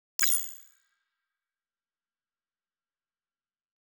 Futurisitc UI Sound 12.wav